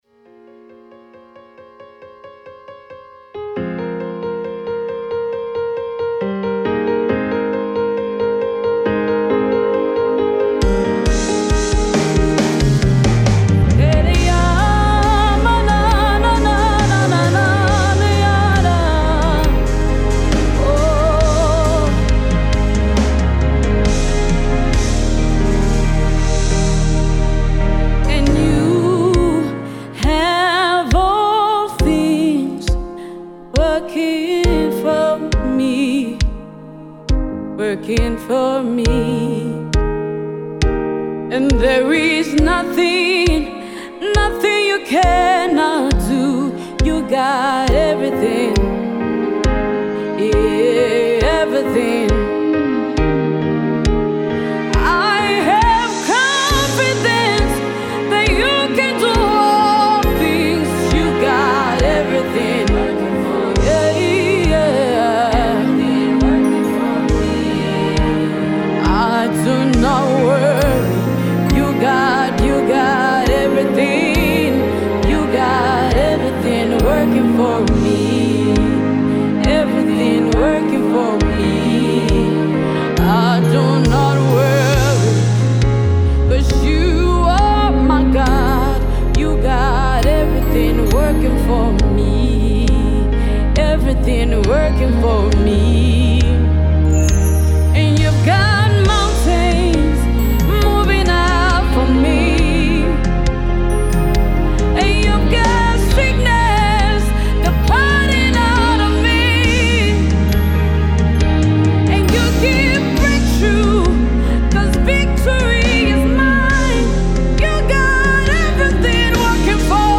soul lifting tune
this song is injected with Heavenly sounds.